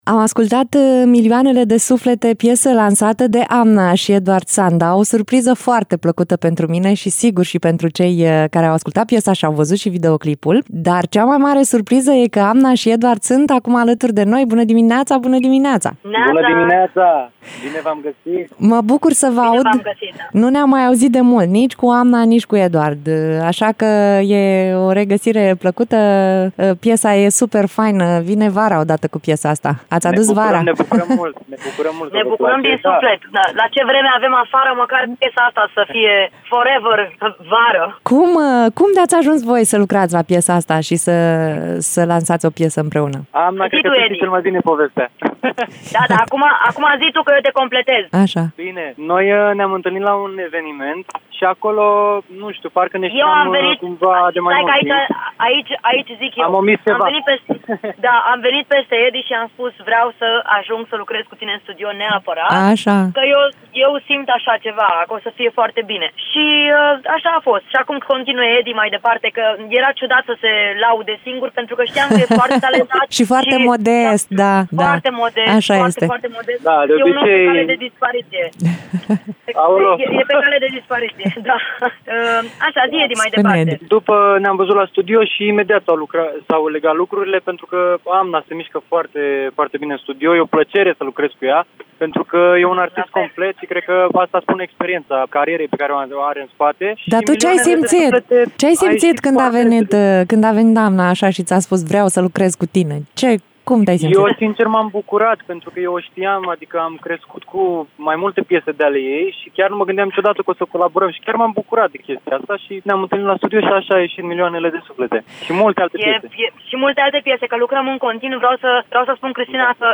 Amna și Edward Sanda, în direct la Radio Iaşi.
Interviu-Amna-si-Edward-Sanda-Editat.mp3